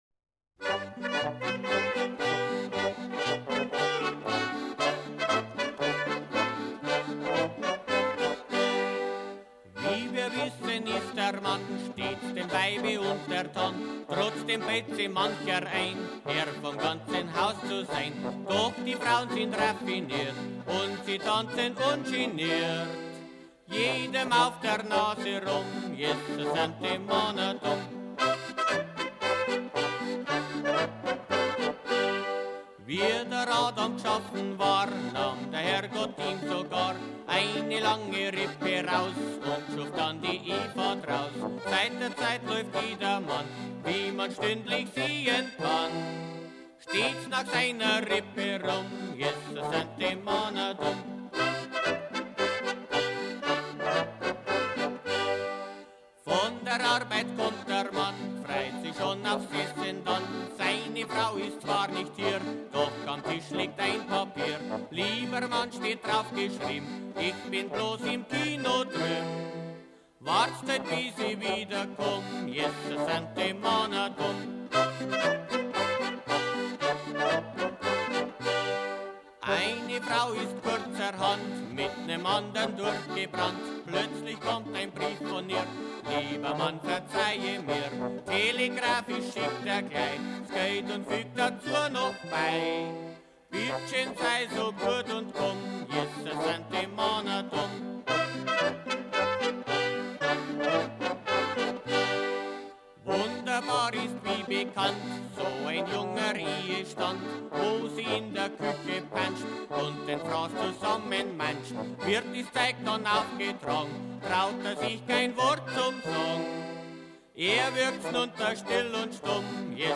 kracherte Gsatanzlmusi